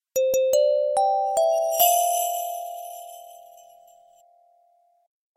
Catégorie SMS